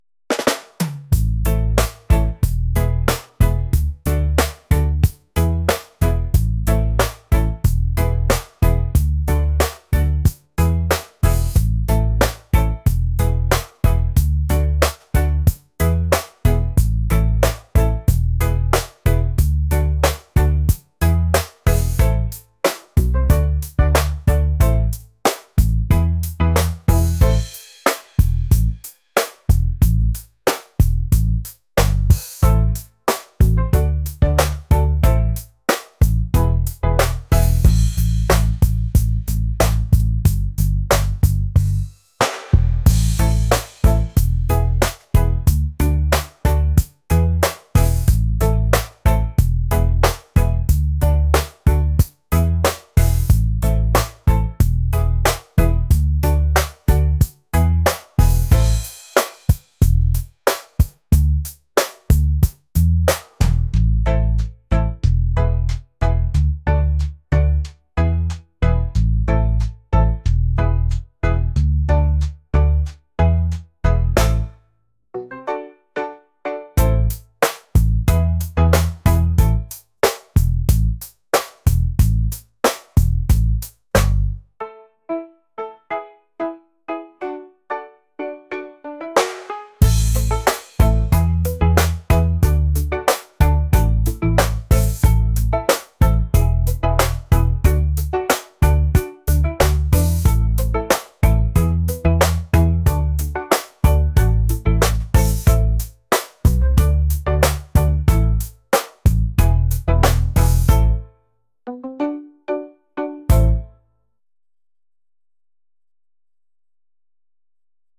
reggae | lofi & chill beats | acoustic